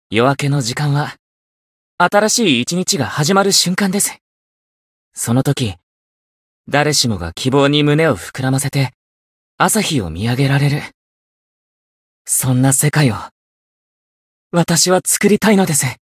限定语音 媒体文件:card_voice_1430_1_1.mp3 黎明时分，是新一天开启的瞬间。